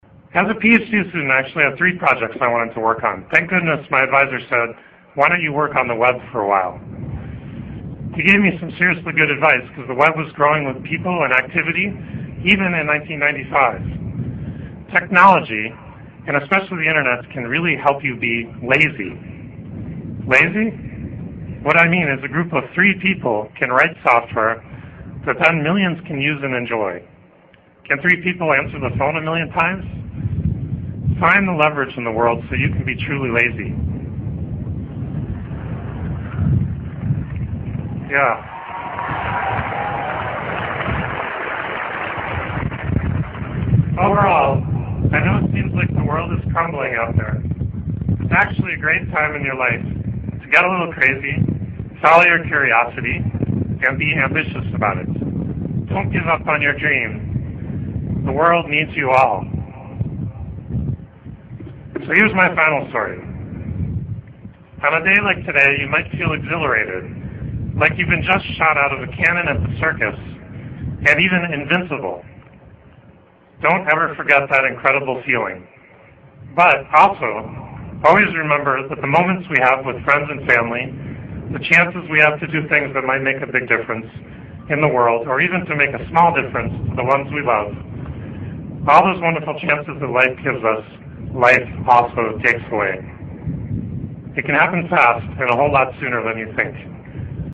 在线英语听力室名人励志英语演讲 第105期:当梦想来临时抓住它(6)的听力文件下载,《名人励志英语演讲》收录了19篇英语演讲，演讲者来自政治、经济、文化等各个领域，分别为国家领袖、政治人物、商界精英、作家记者和娱乐名人，内容附带音频和中英双语字幕。